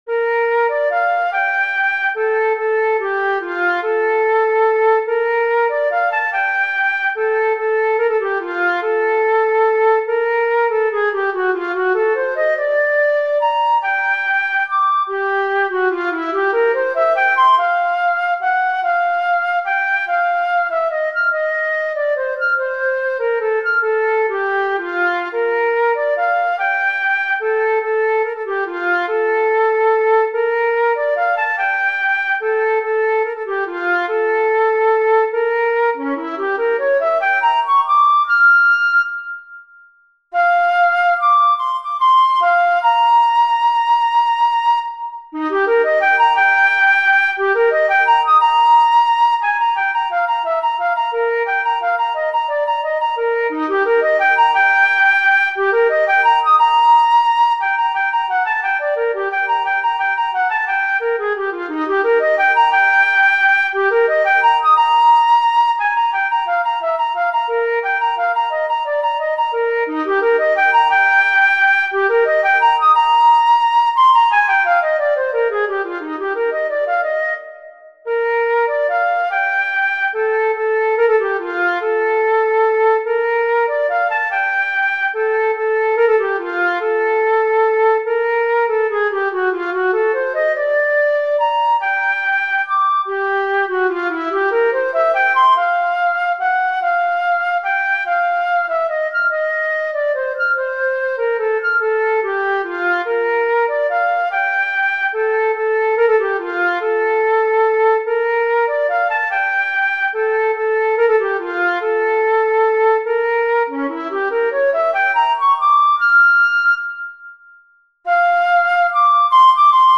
Andantino mosso
– In the Moonlight Andantino mosso Hangkészlet : D’–G'” Előjegyzés : 2b Ütemmutató: 6/8 Tempo: 72 bpm Nehézség: Középhaladó Előadói apparátus: szóló fuvola E_Kohler_Op_66_No_1_7